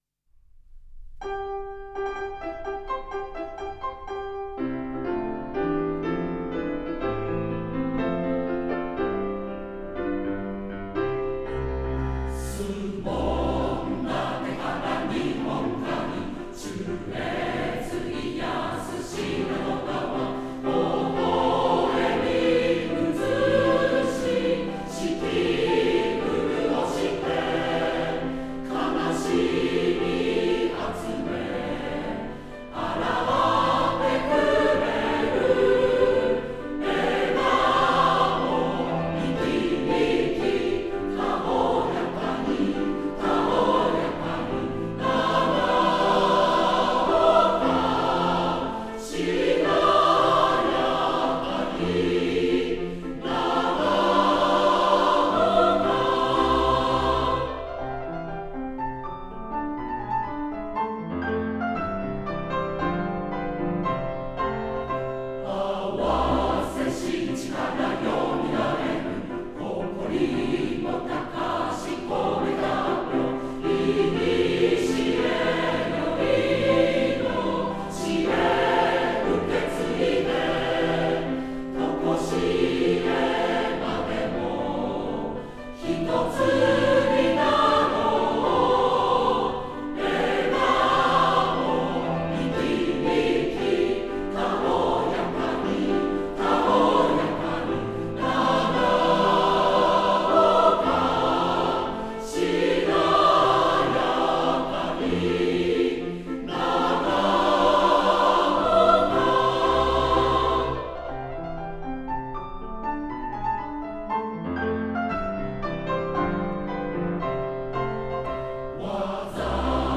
合唱 「合唱：MP3」の画像